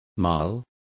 Complete with pronunciation of the translation of marls.